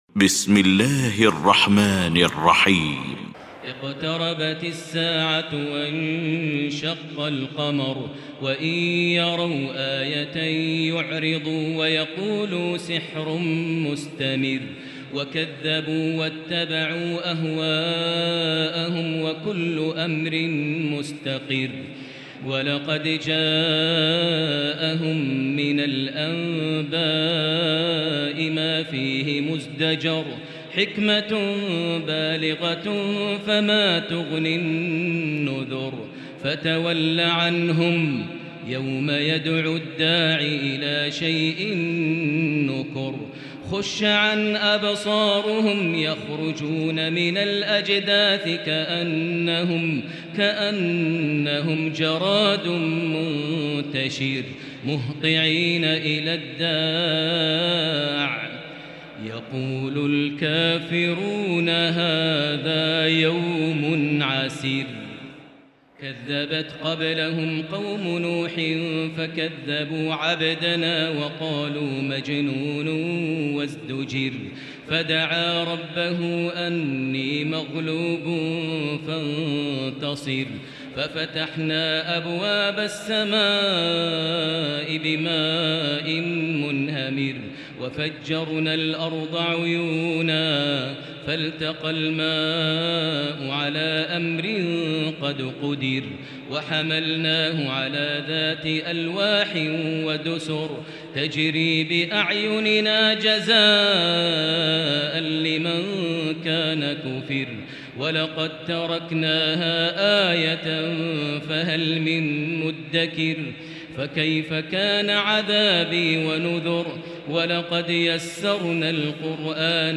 المكان: المسجد الحرام الشيخ: فضيلة الشيخ ماهر المعيقلي فضيلة الشيخ ماهر المعيقلي القمر